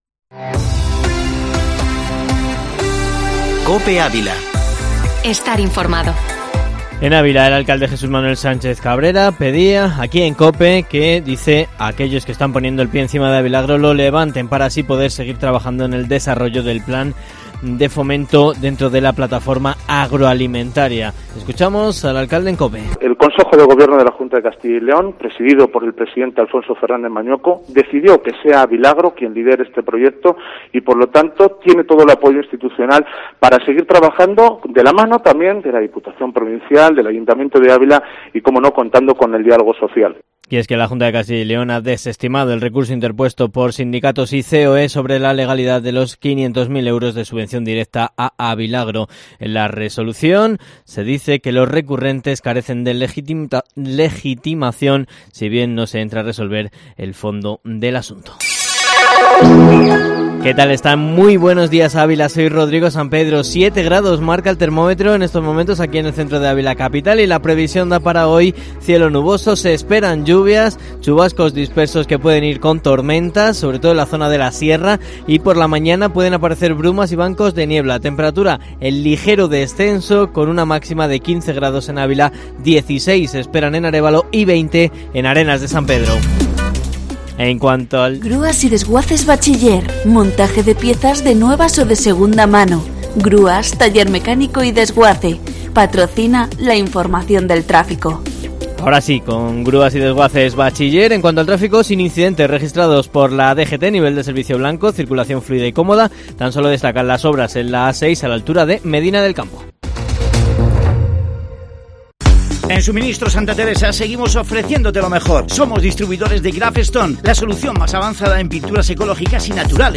Informativo Matinal Herrera en COPE Ávila 20/04/2021